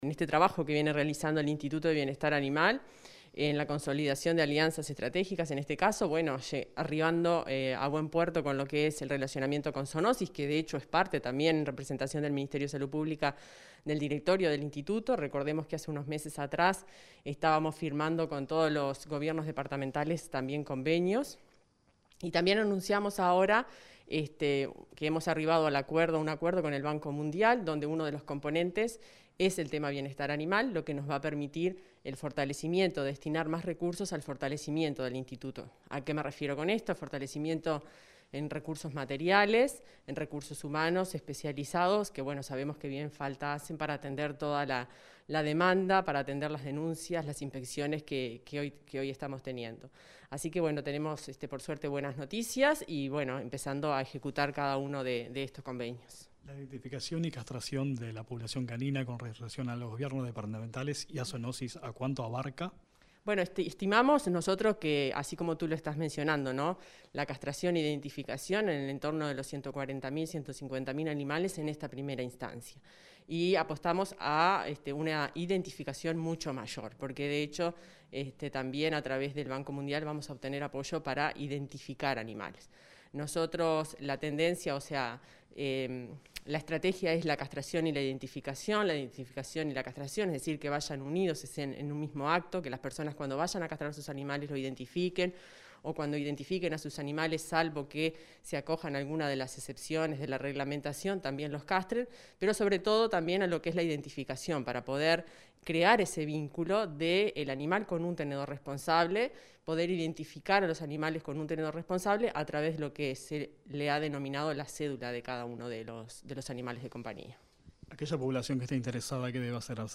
Entrevista a la directora general del MGAP, Fernanda Maldonado
La directora general del Ministerio de Ganadería, Agricultura y Pesca (MGAP), Fernanda Maldonado, dialogó con Comunicación Presidencial sobre los